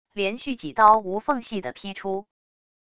连续几刀无缝隙的劈出wav音频